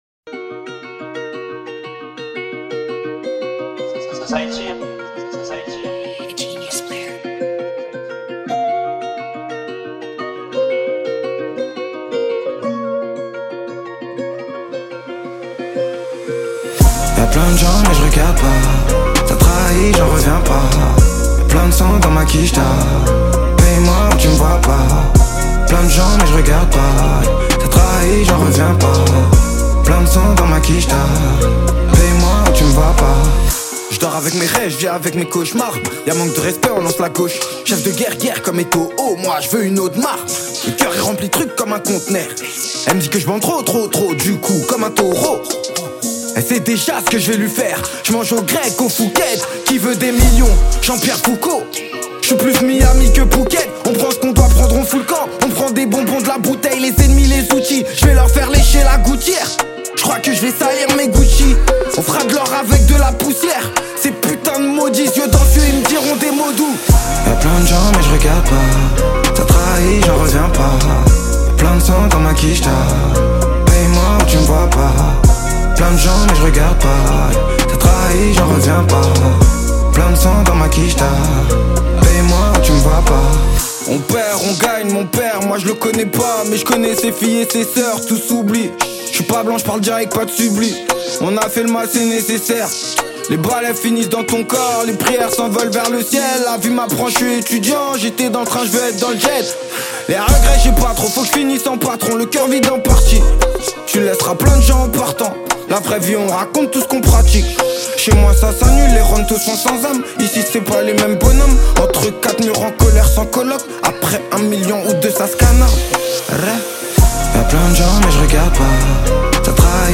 italian trap Télécharger